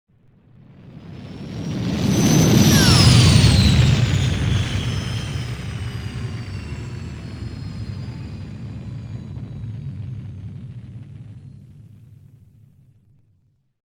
OtherLanding2.wav